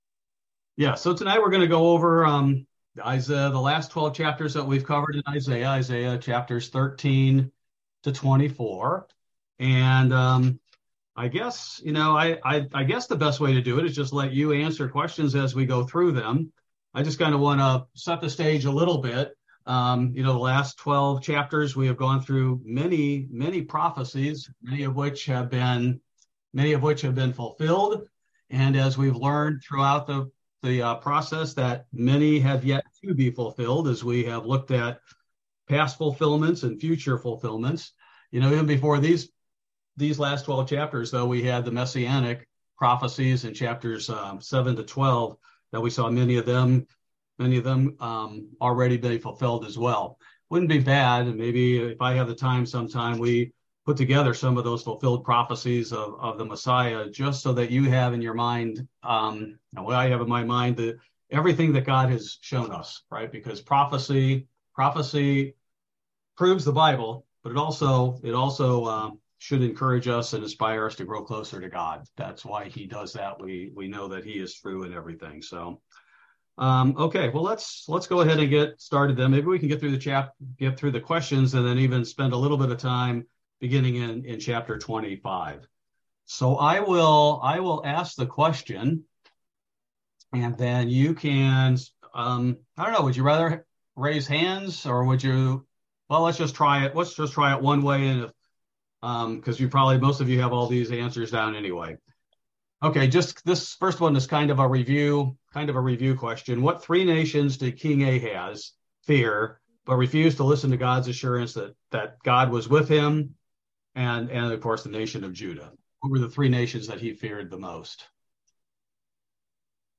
This Bible study primarily focuses on Isaiah 13-24 Bible Study Review Session